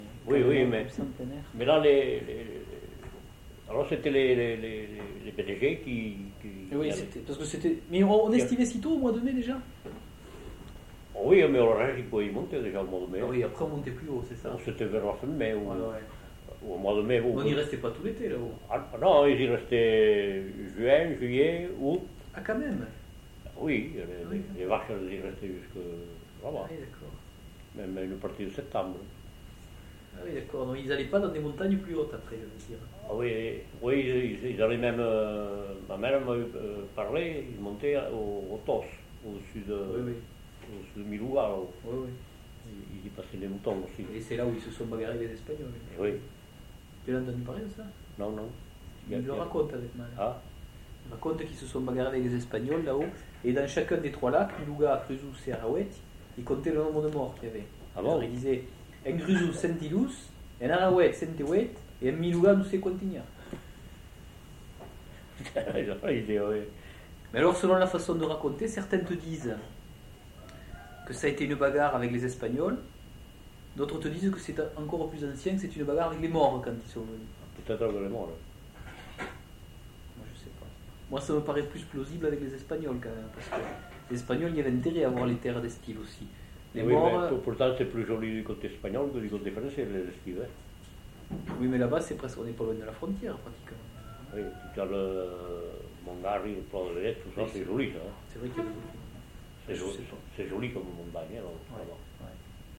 Lieu : Pouech de Luzenac (lieu-dit)
Genre : témoignage thématique